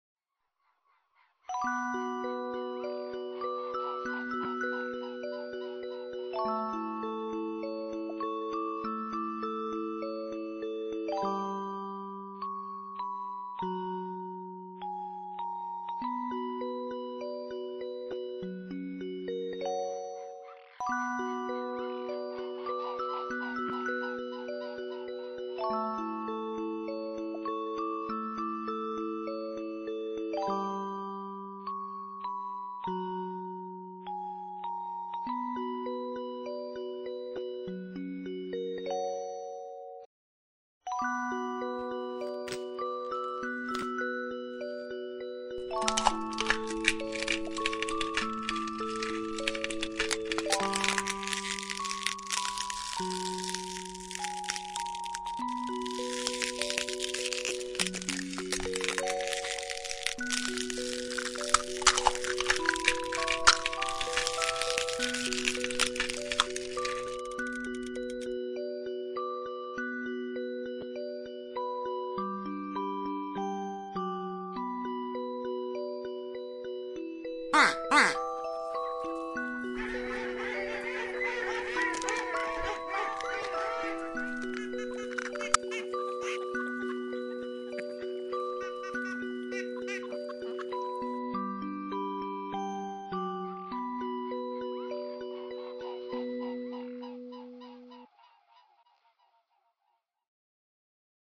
Below is a recording of the music used in the story when the Ugly Duckling hatches.